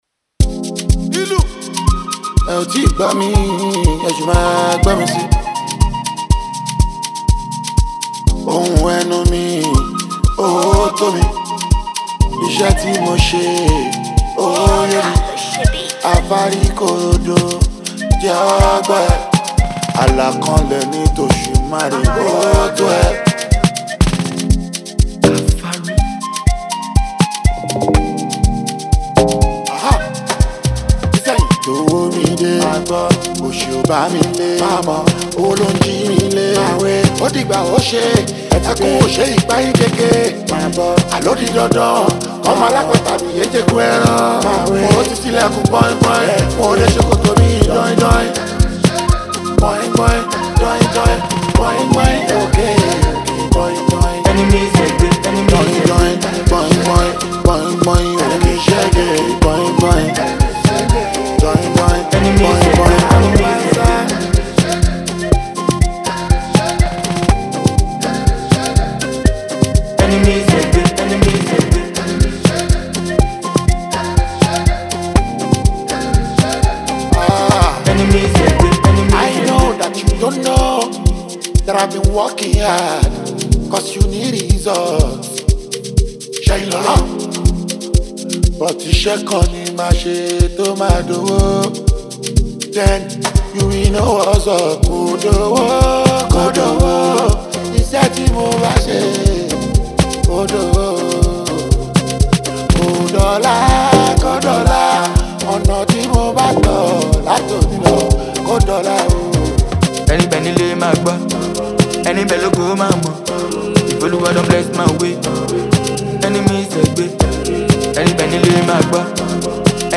mesmerizing beat